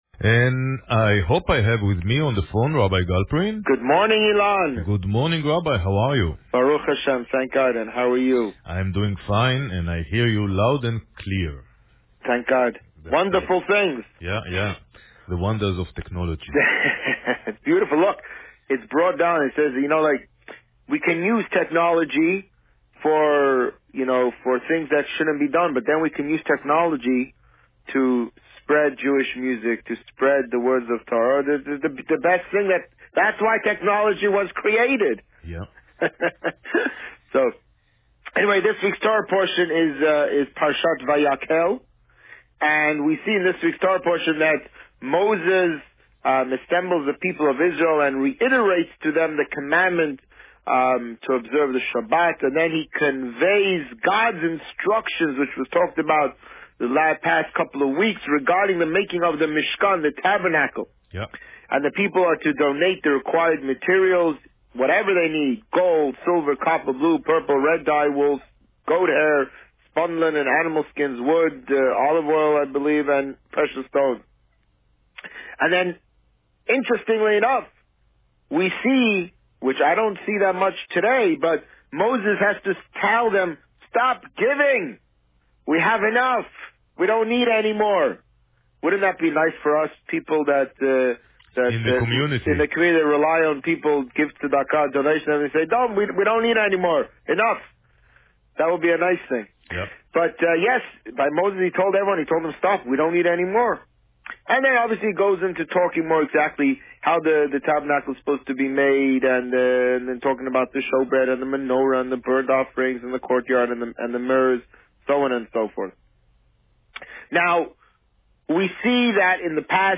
The Rabbi on Radio
On March 3, 2016, the Rabbi spoke about Parsha Vayakhel and the upcoming Purim festivities. Listen to the interview here.